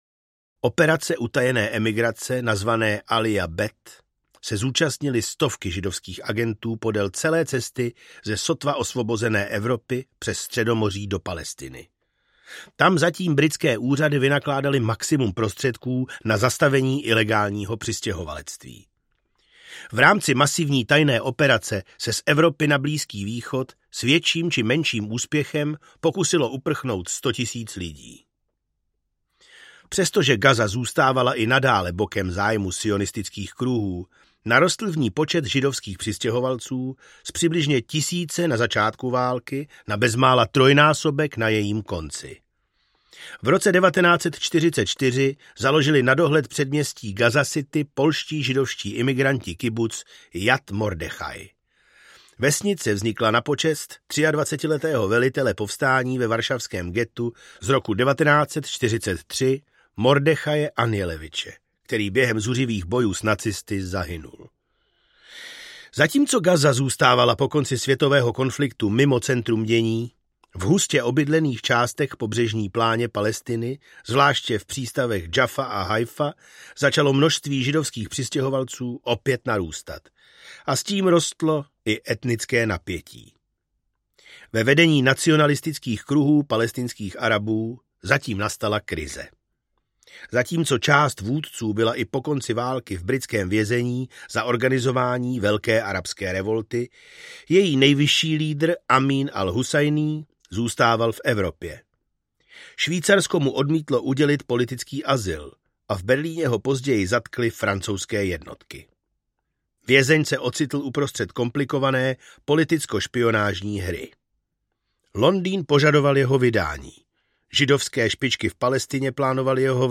Audio knihaMezi mlýnskými kameny: Gaza a její příběh
Ukázka z knihy
• InterpretKryštof Rímský, Jordan Haj